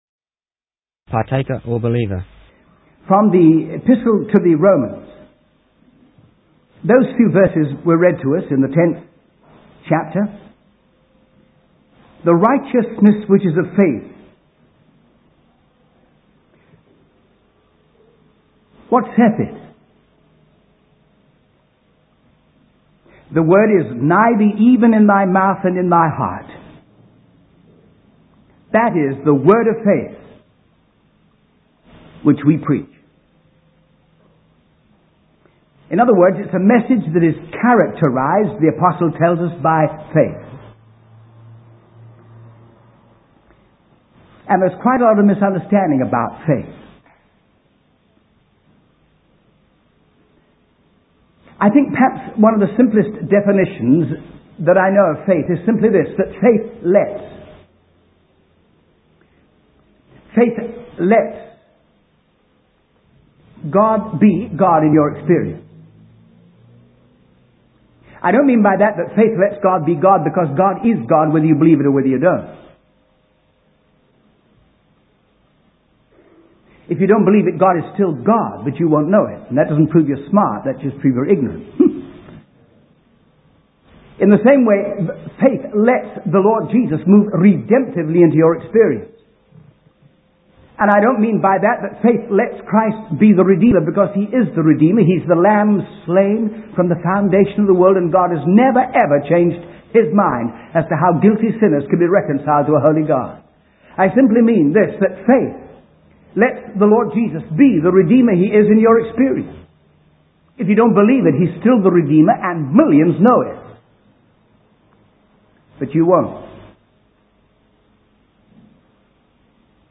The sermon concludes with a call to action, urging individuals to mix their faith with the truth of the Gospel